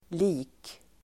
Uttal: [li:k]